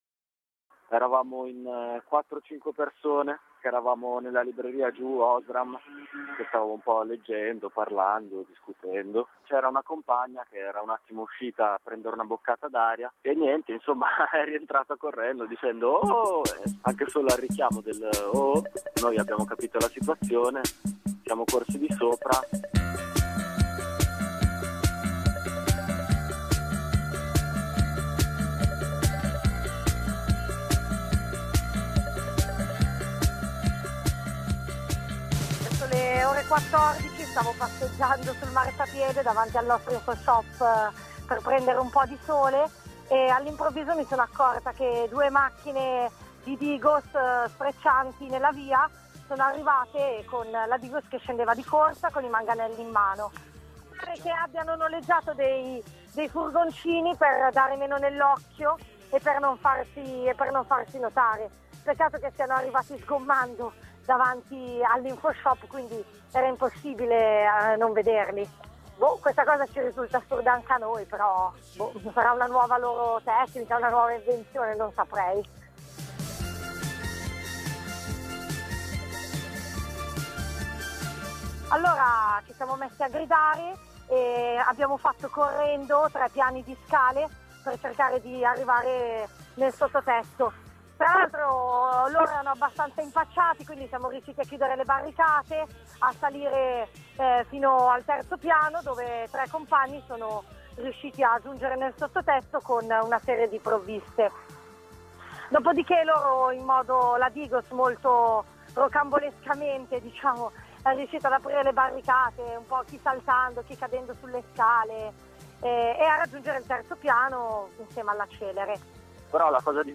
Alcune voci dallo sgombero dell’ardita pizzeria del popolo.